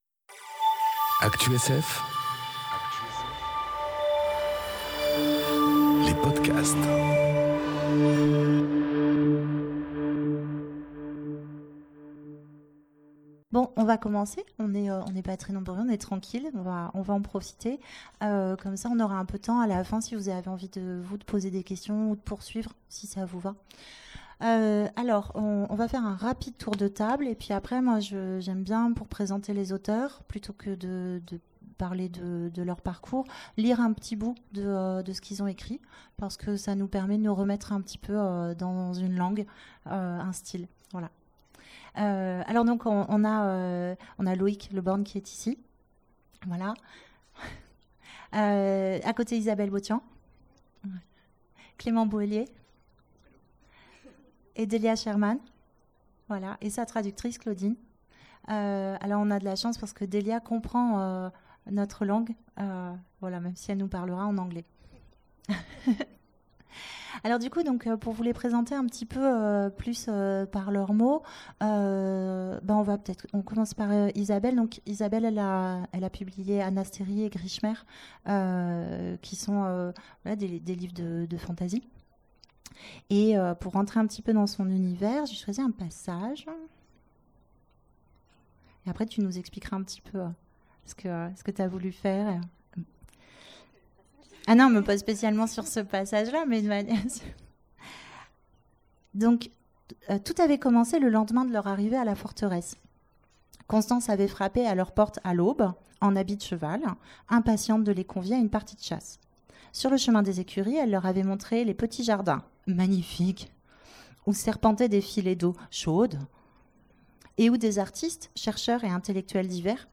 Conférence Vive les voyageurs... De l'imaginaire enregistrée aux Imaginales 2018